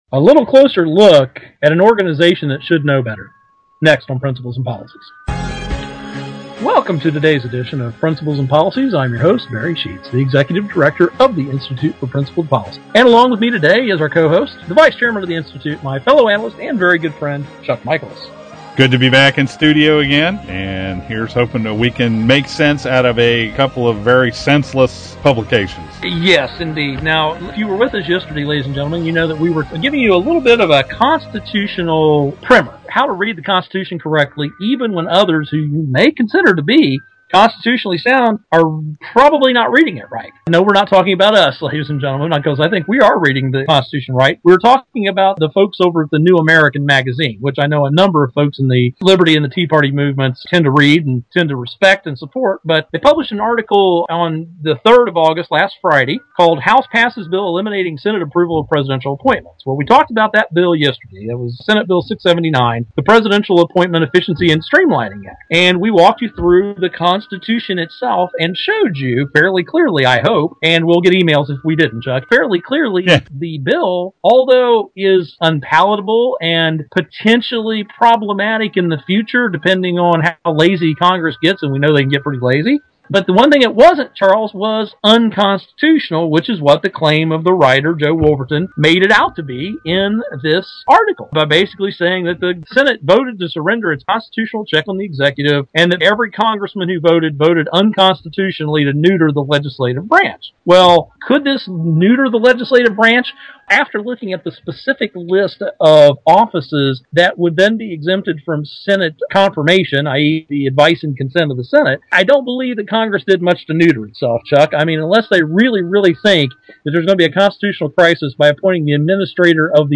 Our Principles and Policies radio show for Tuesday August 7, 2012.